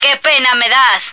flak_m/sounds/female1/est/F1loser.ogg at trunk